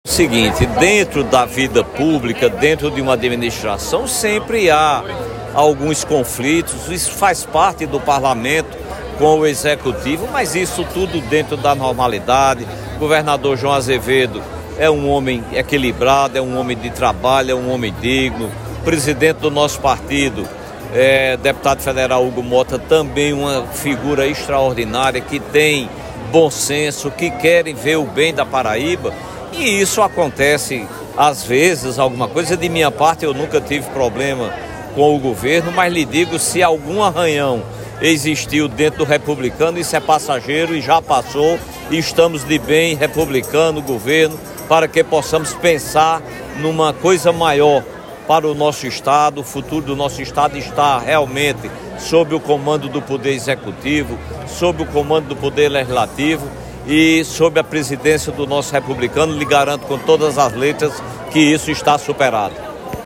Abaixo a fala do deputado estadual Branco Mendes.